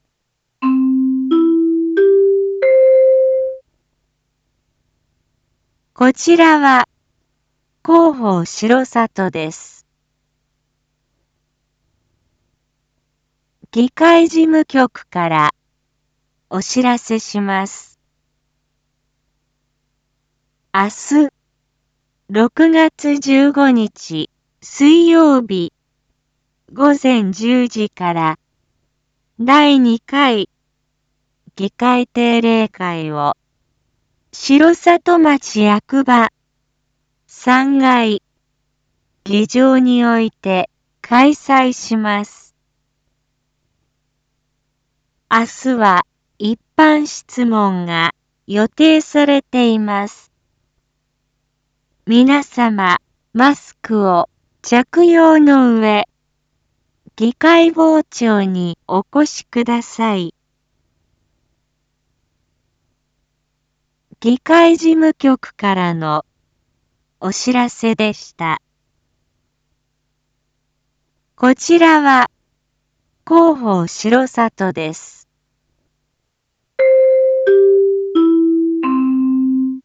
一般放送情報
Back Home 一般放送情報 音声放送 再生 一般放送情報 登録日時：2022-06-14 19:06:21 タイトル：R4.6.14 19時放送分 インフォメーション：こちらは広報しろさとです。